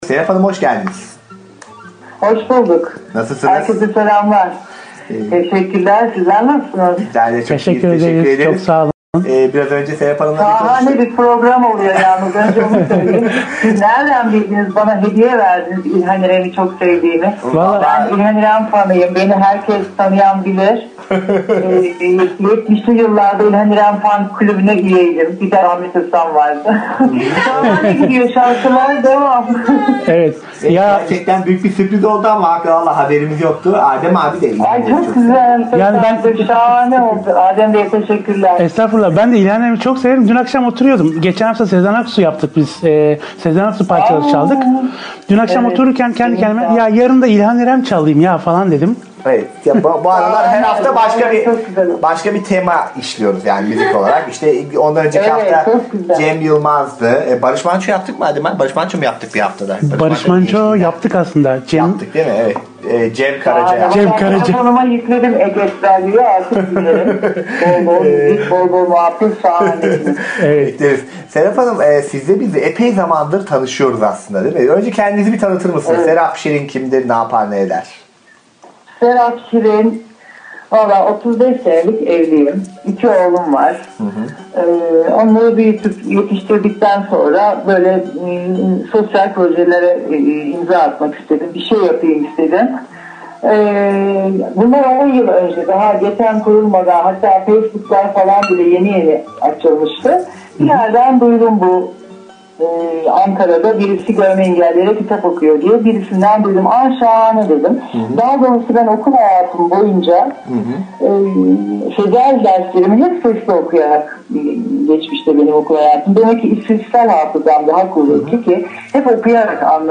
Gönüllü okuyucu röportajları